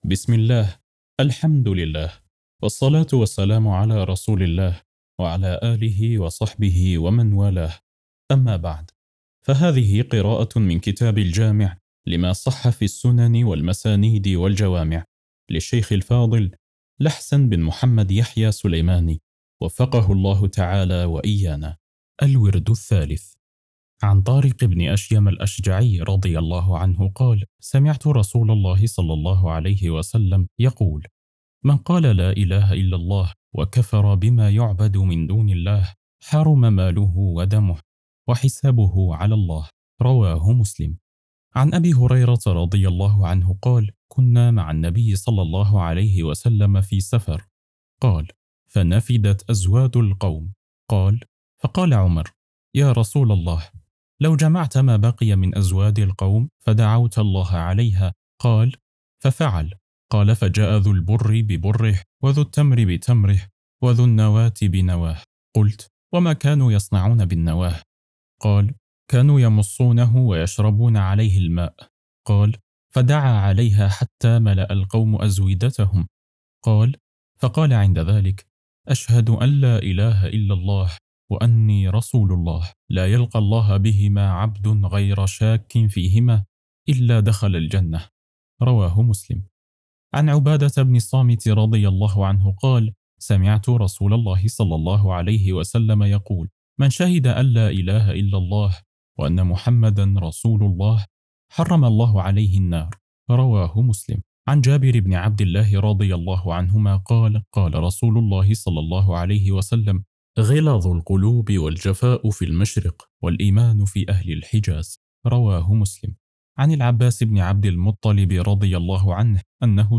قراءة كتاب: الجامع لما صح في السنن والمسانيد والجوامع